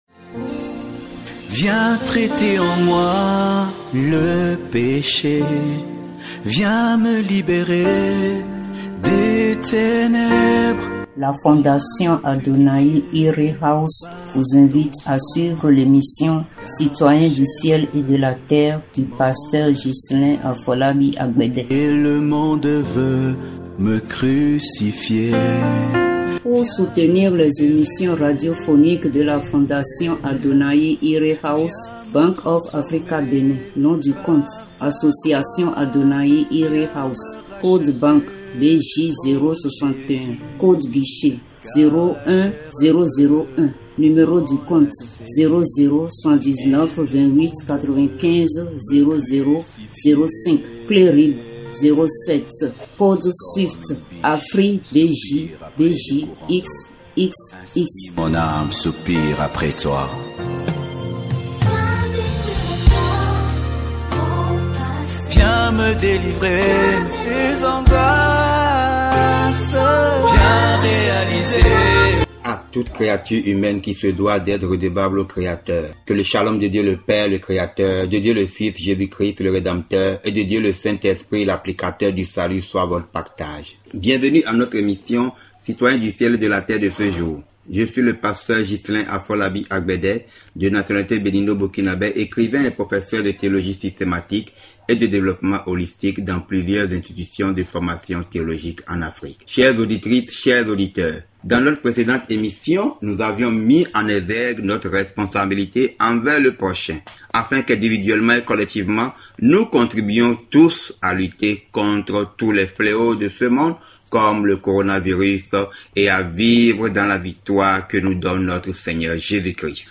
Radio Broadcast Messages